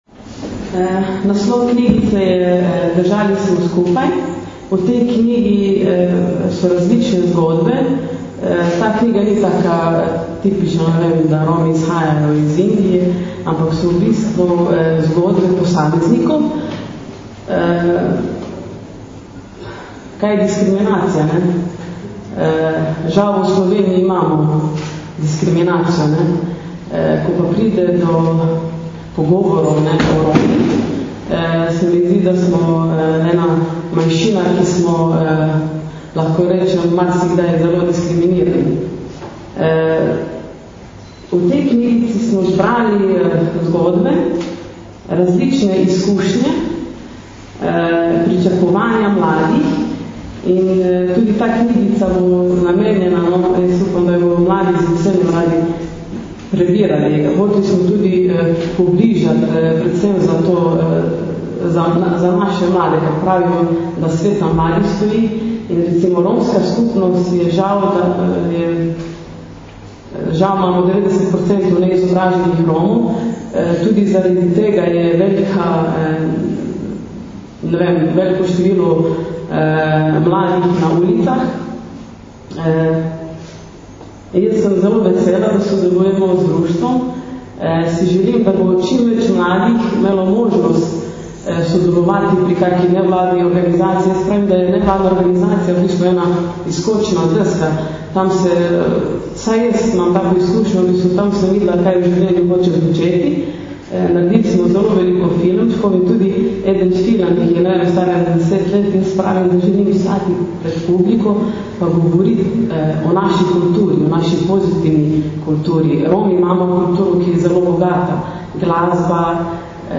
Zvočni posnetek predstavitvi knjižice Držali smo skupaj (mp3)